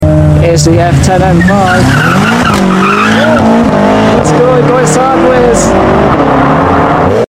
BMW M5 Powerslides Out Of Sound Effects Free Download